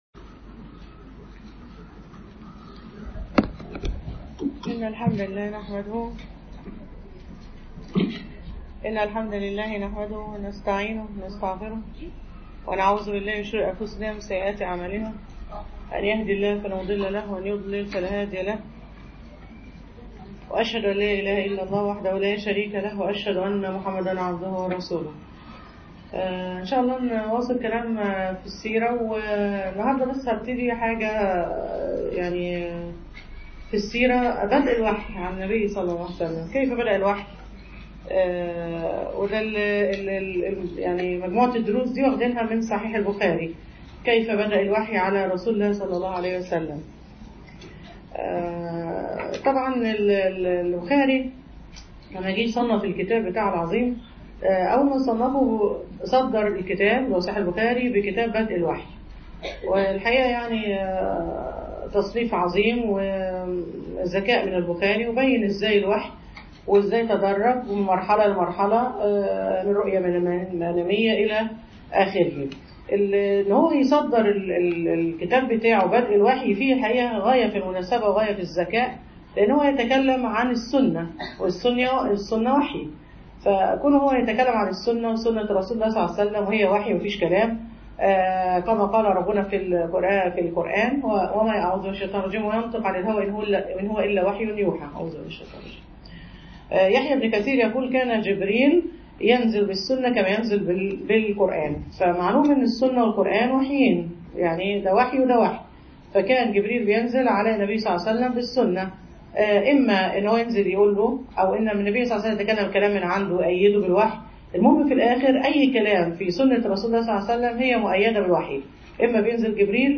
سيرة النبي ﷺ_المحاضرة السادسة